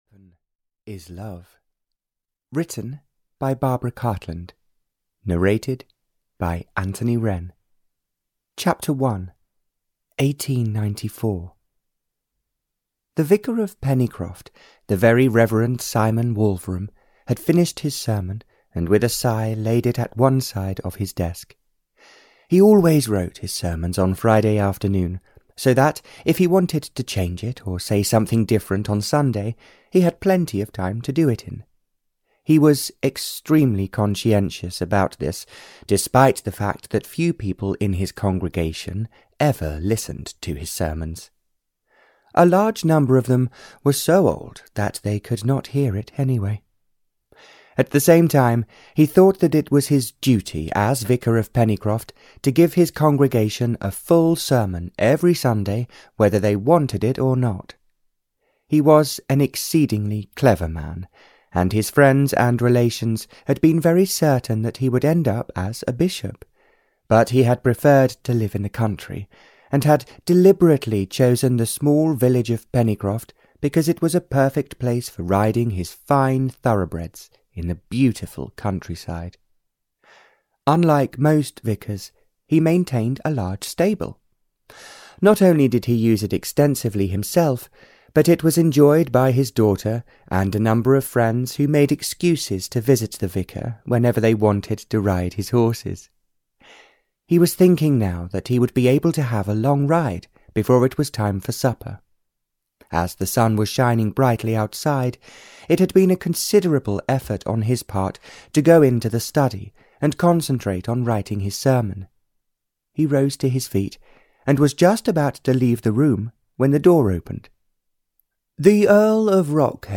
Audio knihaThe Weapon is Love (Barbara Cartland's Pink Collection 146) (EN)
Ukázka z knihy